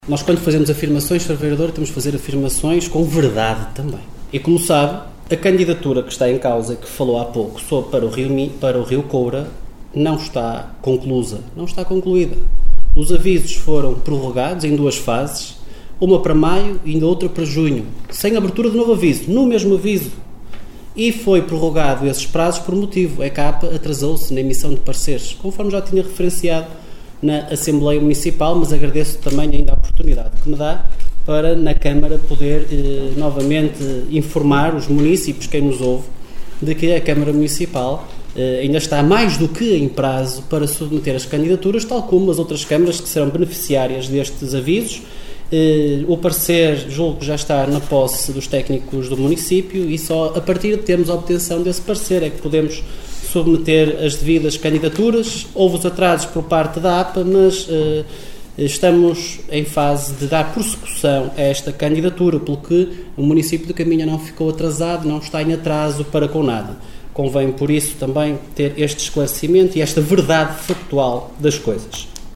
Excertos da última reunião camarária, realizada ontem no Salão Nobre dos Paços do Concelho.